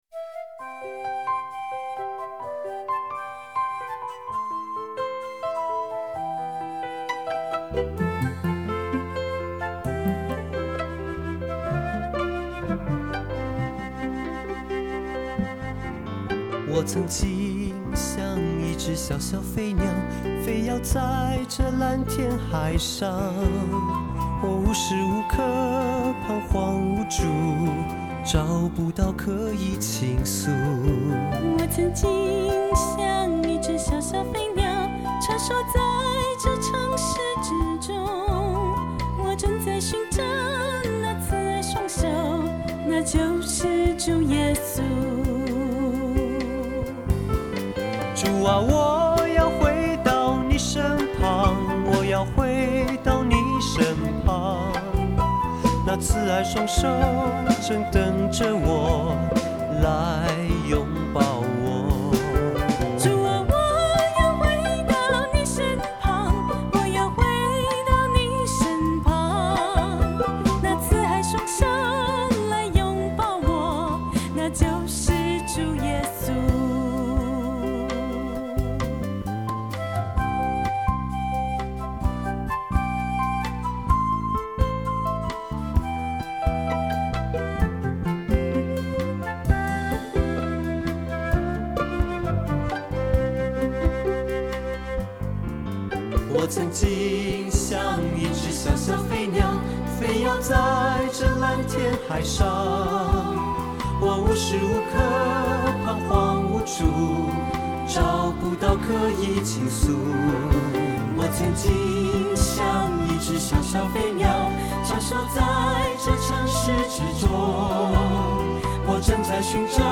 敬拜 090201